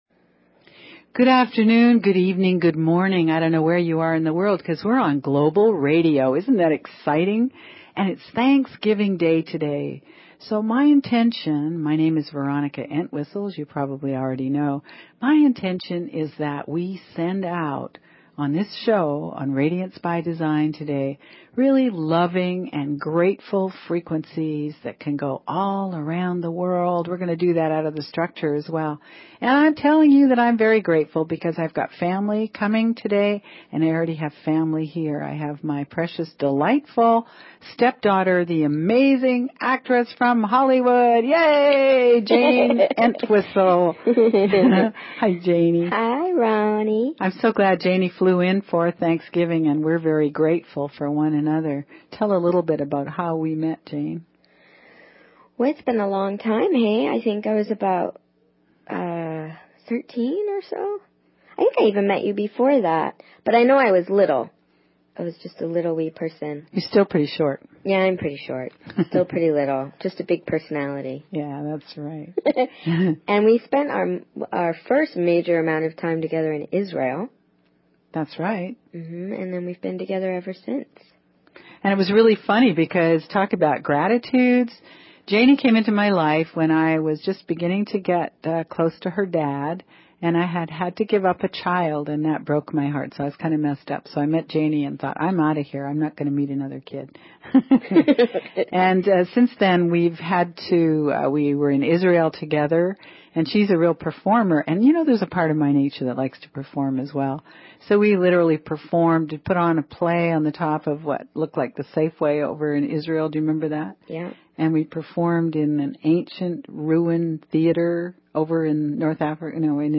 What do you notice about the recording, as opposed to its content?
Radiance is a call in show so call in about your life, your questions, the trickery that you find in your daily routine.